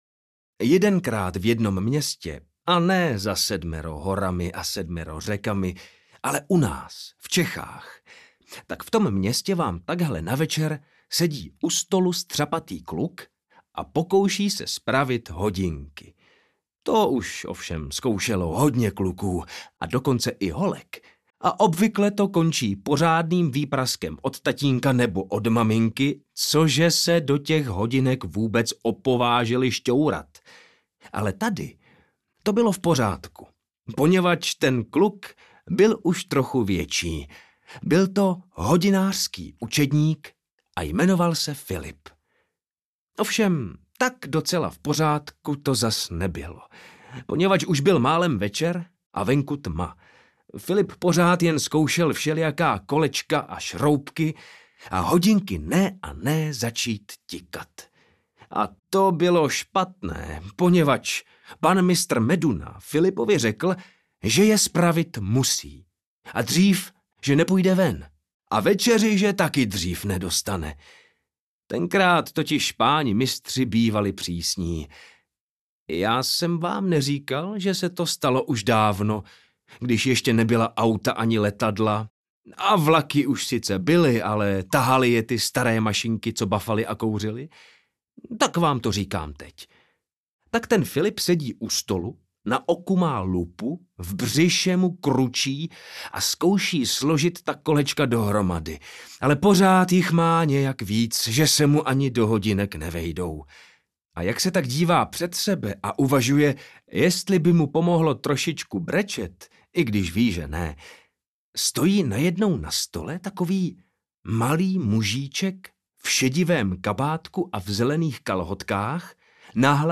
O hodináři Filipovi a skřítku Hodináříčkovi audiokniha
Ukázka z knihy